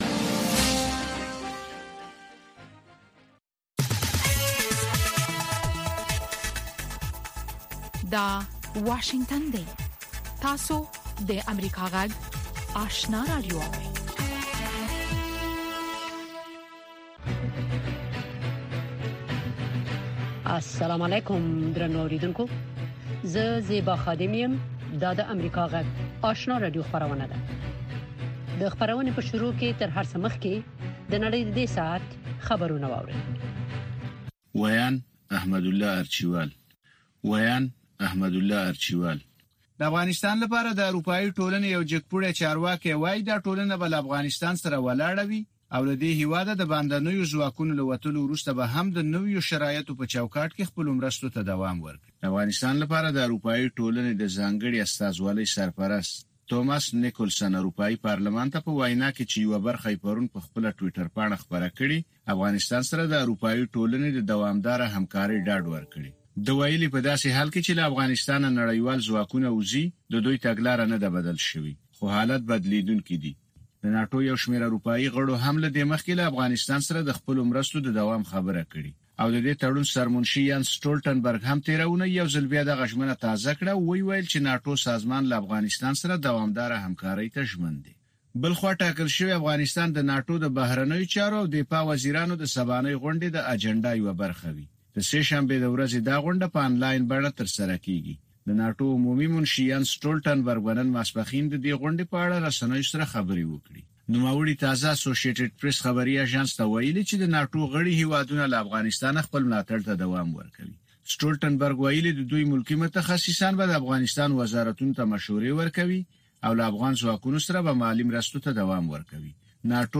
دوهمه ماښامنۍ خبري خپرونه